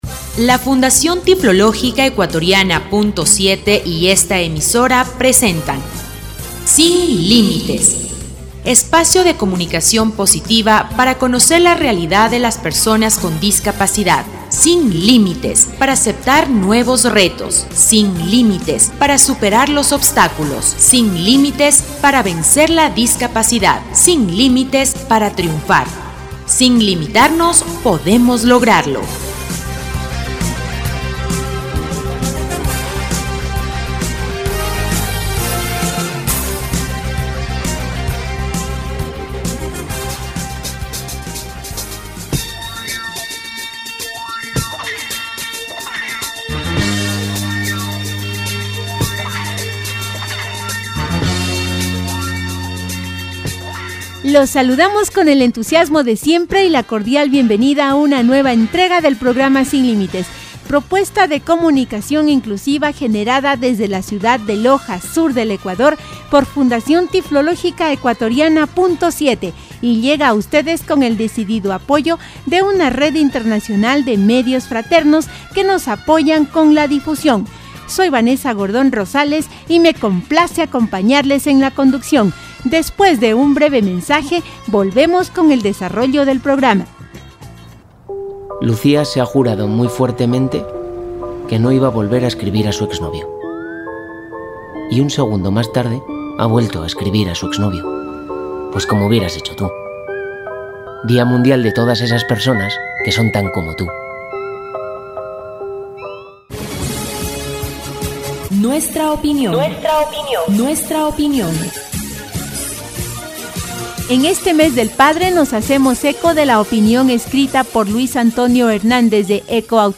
Espacio de comunicación positiva para conocer la realidad de las personas con discapacidad, disfruta de una nueva edición del programa radial «Sin Límites».